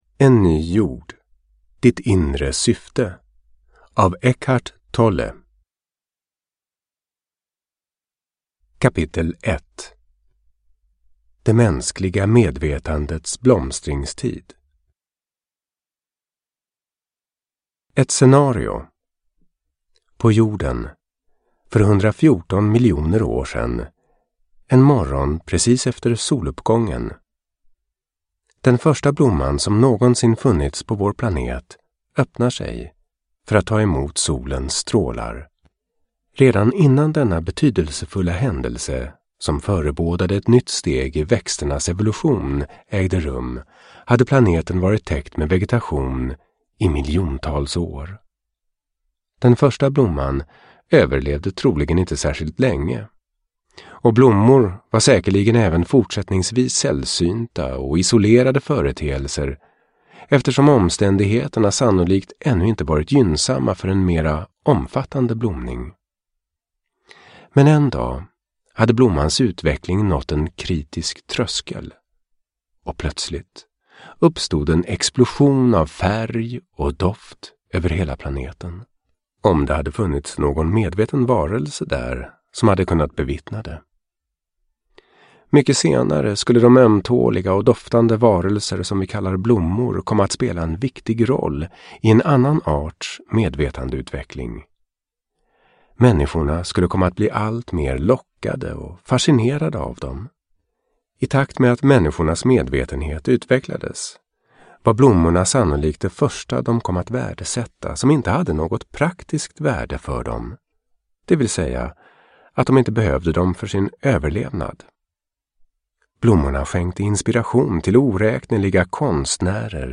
En ny jord : ditt inre syfte – Ljudbok – Laddas ner